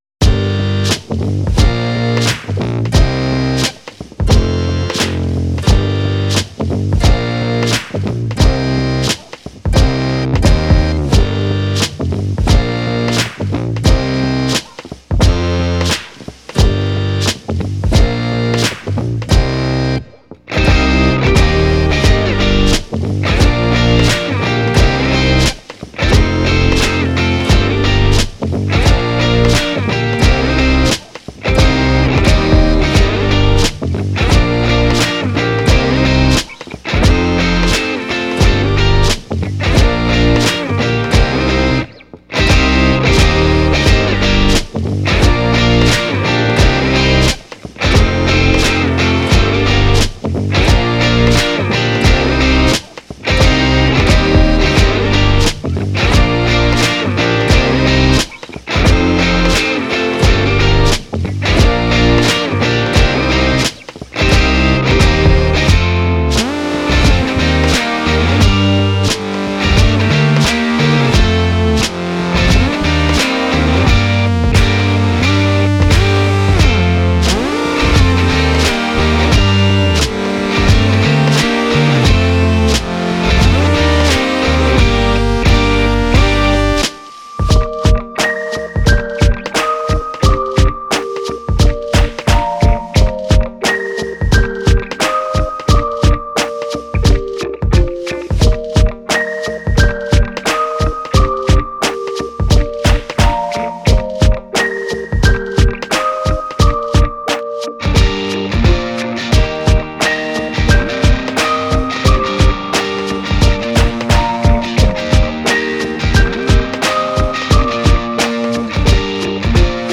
Alt-Pop